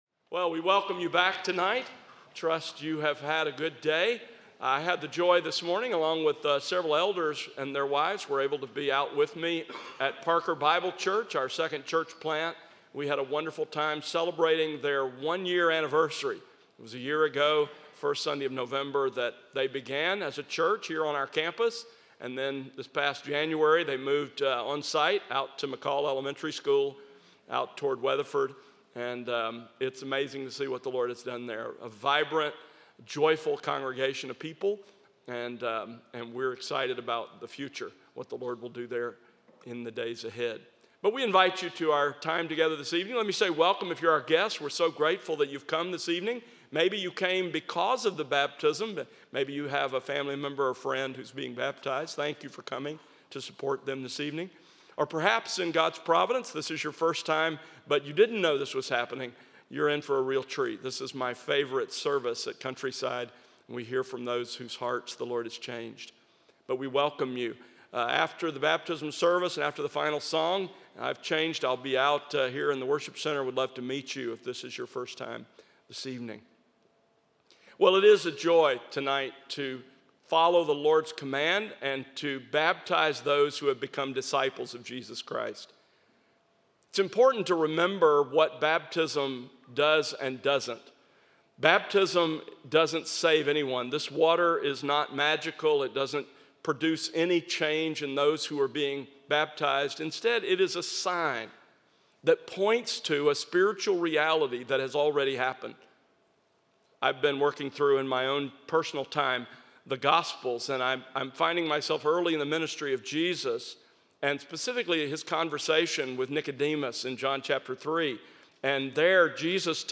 Baptisms